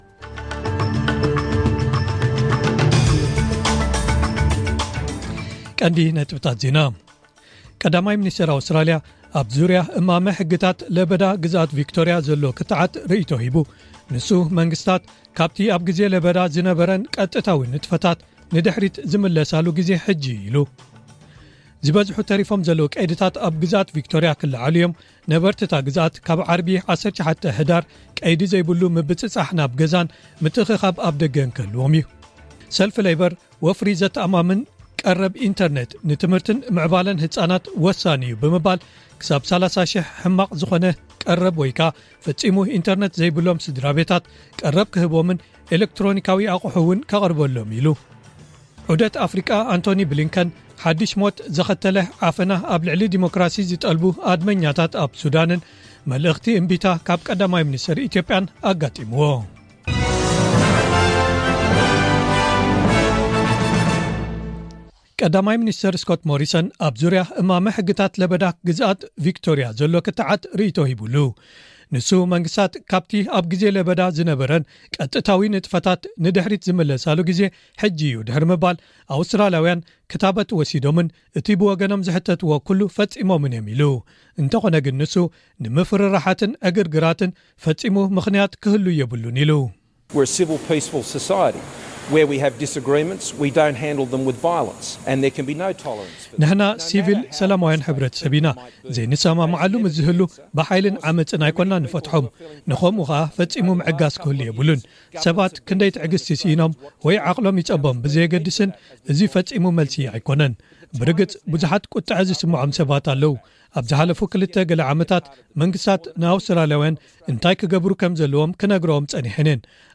ዕለታዊ ዜና ኤስቢኤስ ትግርኛ (18/11/2021)